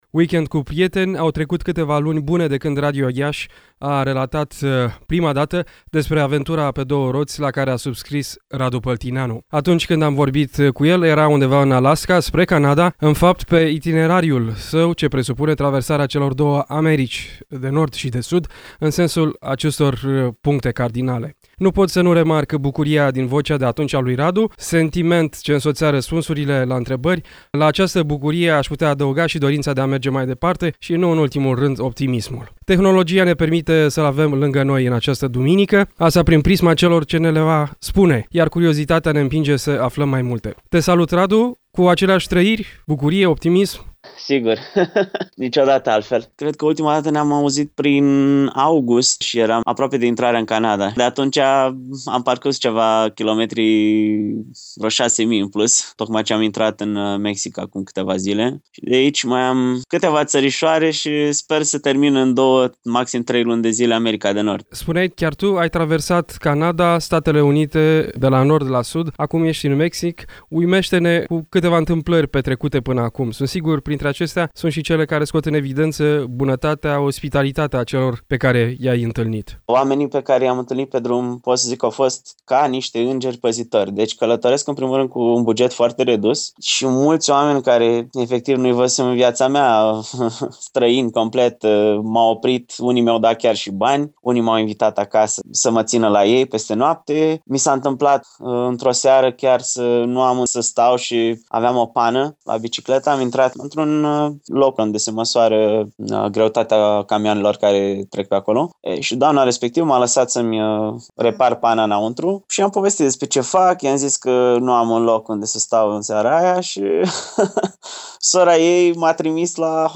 Tehnologia ne-a permis să-l ”avem” lângă noi la ”Weekend cu prieteni”, asta și prin prisma celor ce spuse.